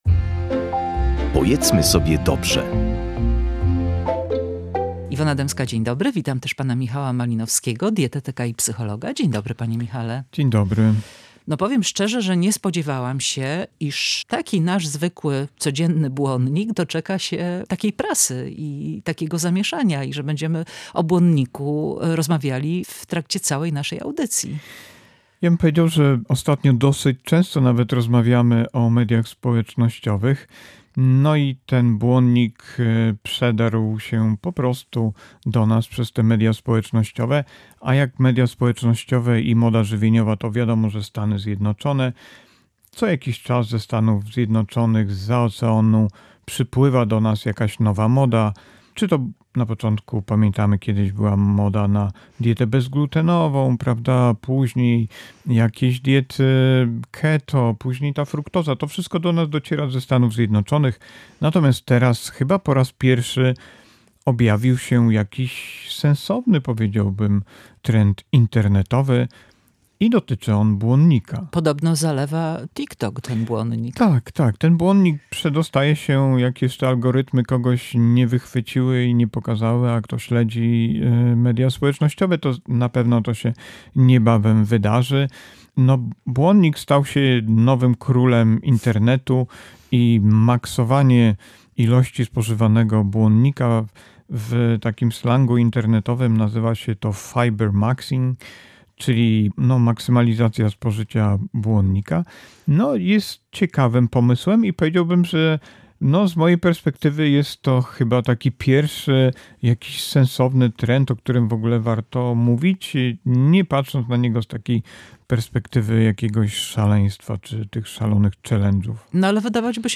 rozmawia z dietetykiem i psychologiem